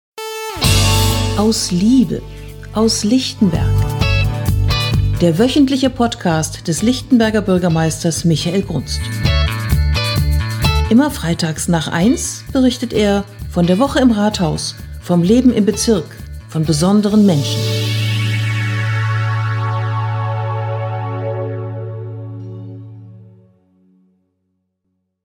Podcast-Intro: